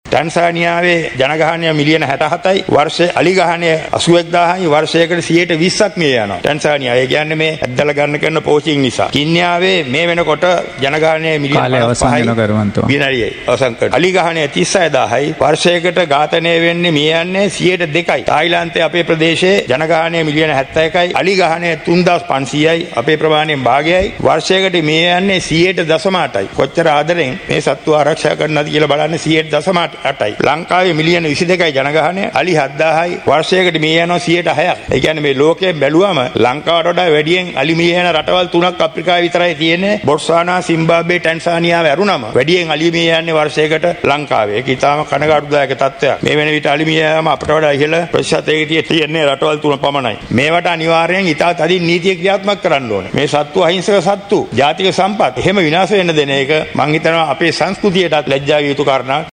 අද පාර්ලිමේන්තුවේදී ඔහු මේ බව සදහන් කළා.